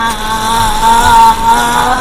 Vtuberさん配信中に男の声が入ってしまうｗｗｗ